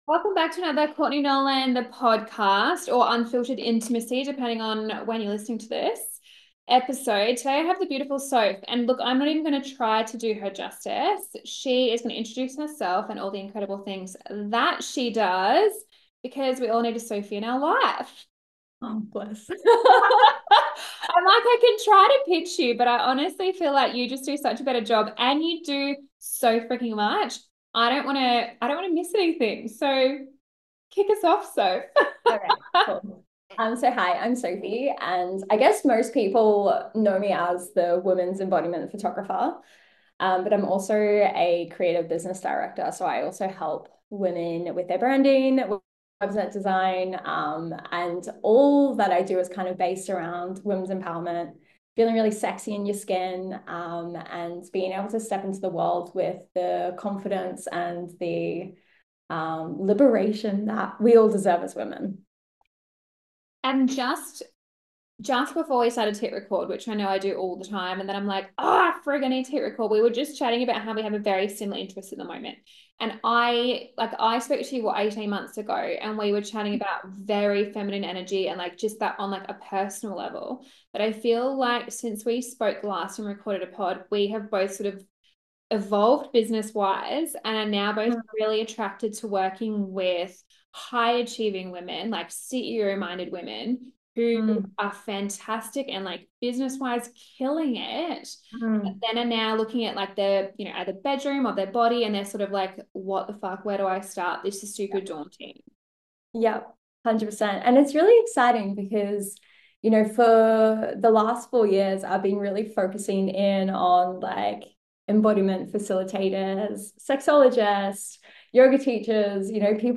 In this empowering conversation